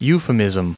1479_euphemism.ogg